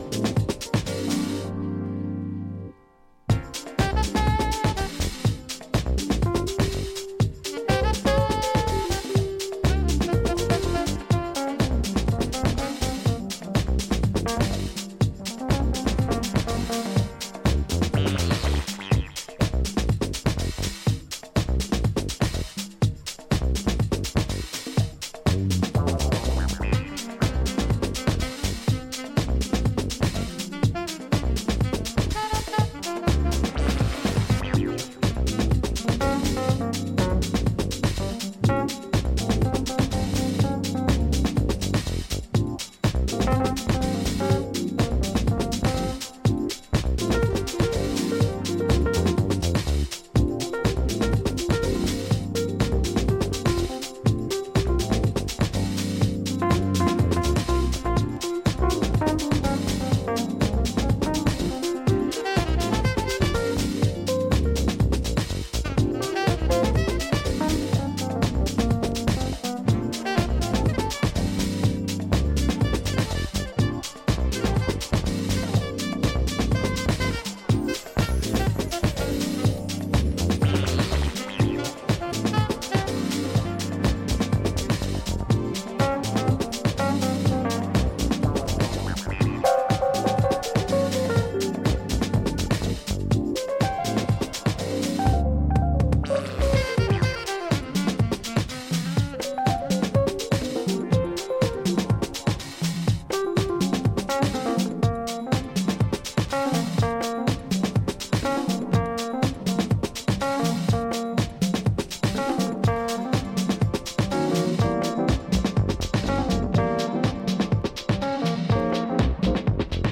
ハウス、ジャズ、ブレイクビーツがブレンドされたおすすめ盤です！